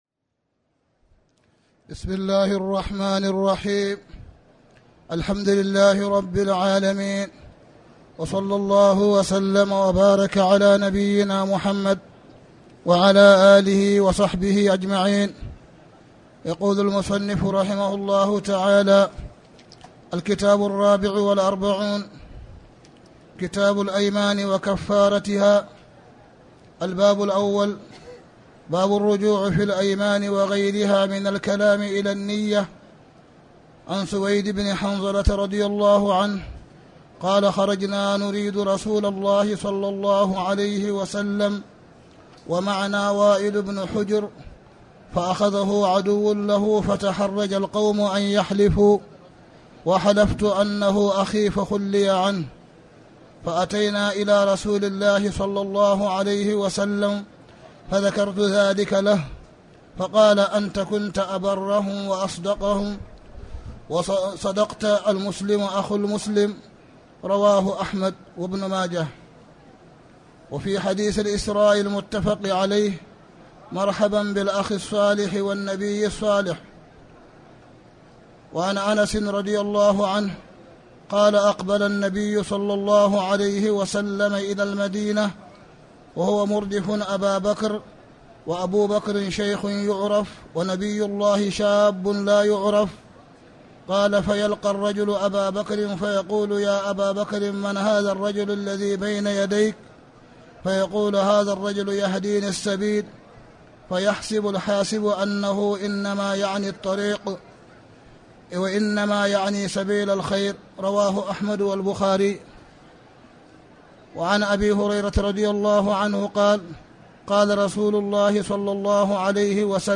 تاريخ النشر ١٩ رمضان ١٤٣٩ هـ المكان: المسجد الحرام الشيخ: معالي الشيخ أ.د. صالح بن عبدالله بن حميد معالي الشيخ أ.د. صالح بن عبدالله بن حميد كتاب الأيمان وكفارتها